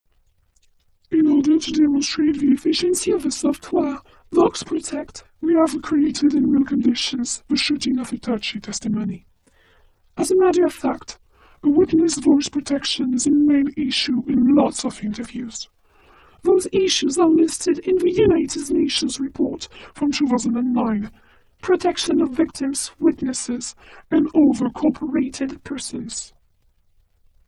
Now let's take the example of the same original file processed by VOX PROTECT in high-level protection:
SONOGRAM OF THE VOICE PROCESSED BY VOX PROTECT IN HIGH LEVEL OF PROTECTION
The VOX PROTECT effect acts on the signal’s harmonics, making speech recognition impossible.
AUDIO-EN-WITNESS-VOXPROTECT-2_01.wav